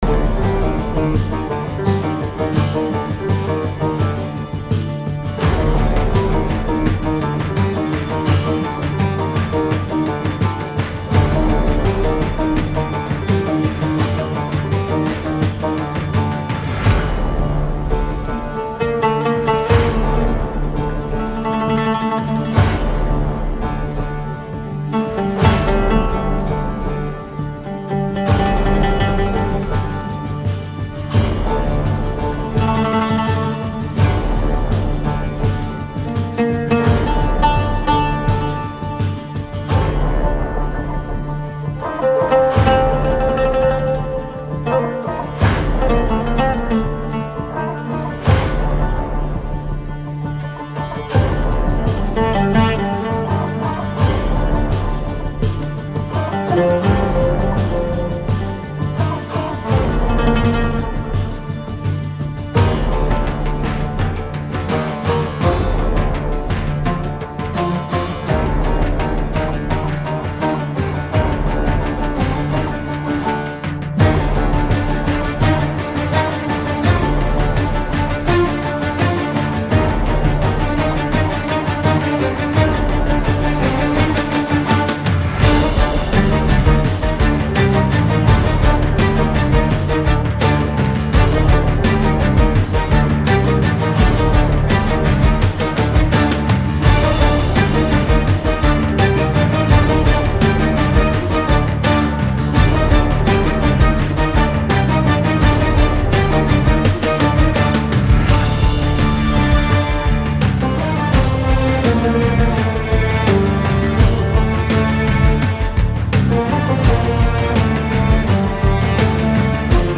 tema dizi müziği, duygusal heyecan aksiyon fon müziği.